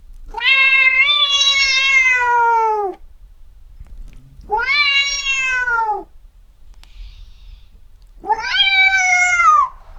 white-cat-screams-in-terr-e3yi42px.wav